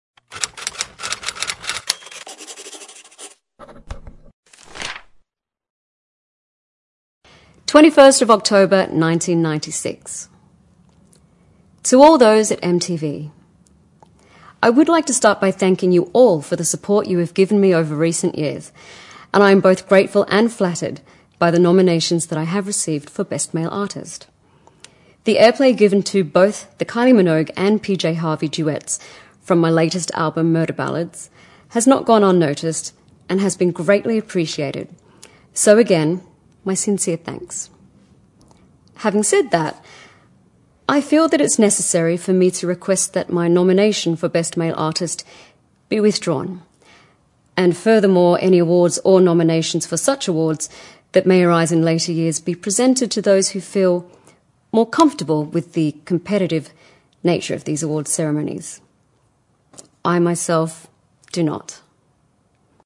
见信如晤Letters Live 第12期:'凯丽米洛'读信:我的缪斯女神不是一匹马(1) 听力文件下载—在线英语听力室
在线英语听力室见信如晤Letters Live 第12期:'凯丽米洛'读信:我的缪斯女神不是一匹马(1)的听力文件下载,《见信如唔 Letters Live》是英国一档书信朗读节目，旨在向向书信艺术致敬，邀请音乐、影视、文艺界的名人，如卷福、抖森等，现场朗读近一个世纪以来令人难忘的书信。